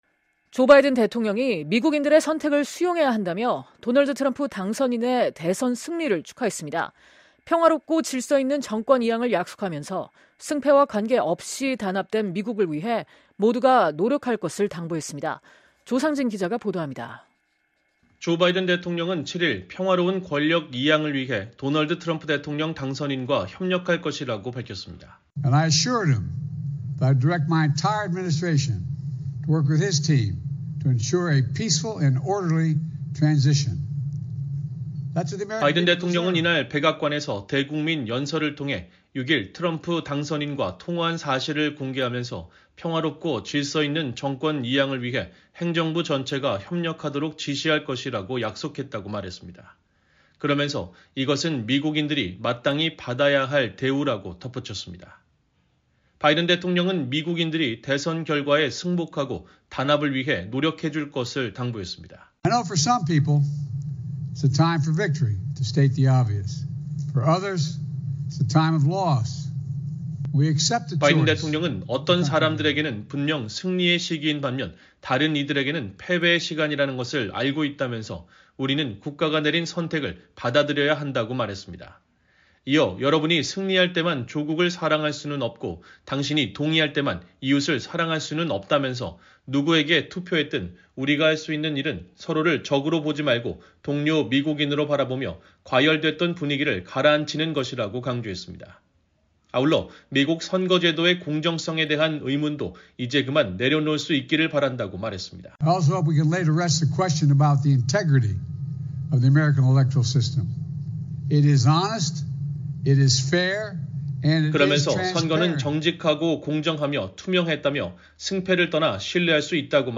[녹취: 바이든 대통령] “And I assured him that I would direct my entire administration to work with his team to ensure a peaceful and orderly transition.
바이든 대통령은 이날 백악관에서 대국민 연설을 통해 6일 트럼프 당선인과 통화한 사실을 공개하면서 “평화롭고 질서 있는 정권 이양을 위해 행정부 전체가 협력하도록 지시할 것이라고 약속했다”고 말했습니다.